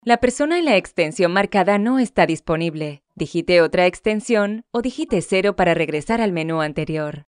女西103 西班牙语女声 稳重 略浑厚 低沉|激情激昂|大气浑厚磁性|沉稳|娓娓道来|科技感|积极向上|时尚活力|神秘性感|调性走心|亲切甜美|感人煽情|素人 00:00 01:28 点击下载 点击收藏 女西103 西班牙语女声 年轻活力 低沉|激情激昂|大气浑厚磁性|沉稳|娓娓道来|科技感|积极向上|时尚活力|神秘性感|调性走心|亲切甜美|感人煽情|素人 00:00 01:28 点击下载 点击收藏